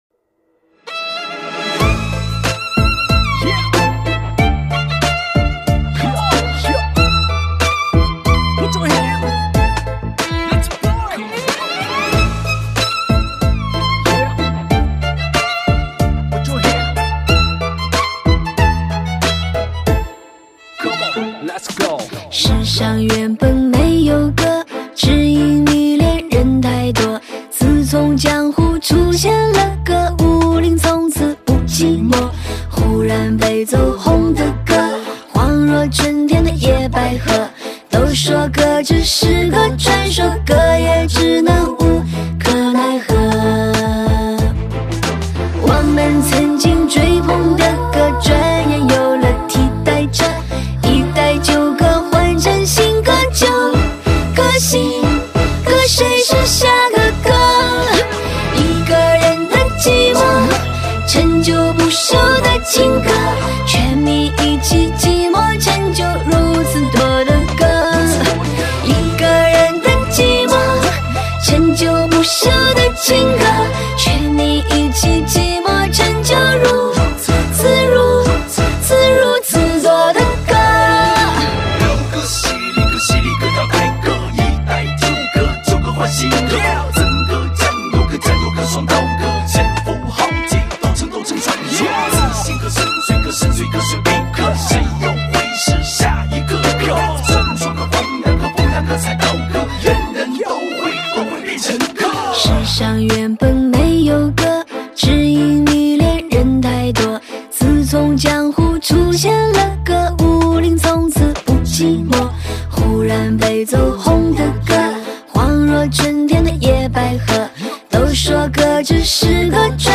伤心绝唱，精选好歌，伤感至极，聆听音乐感受音乐……